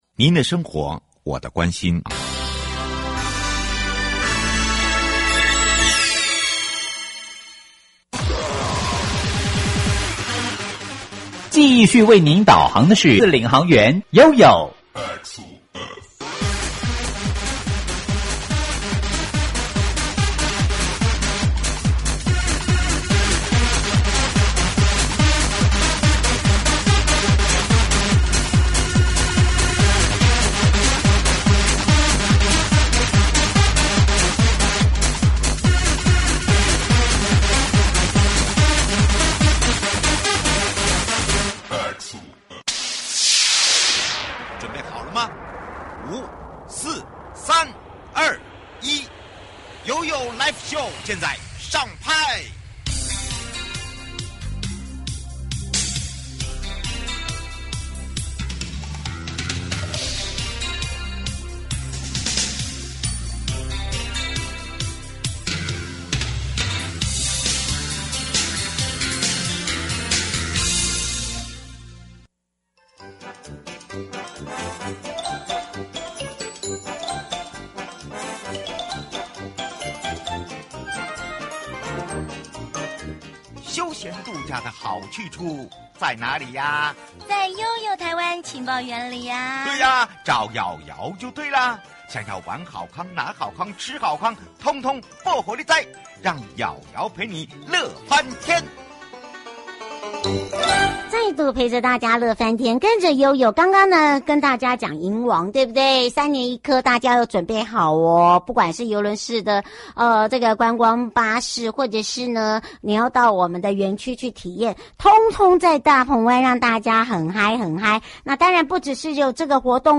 體驗農遊穿梭於宜蘭市南北館傳統市場之間，探索各類攤商與老店 受訪者： 1.大鵬灣管理處 許主龍處長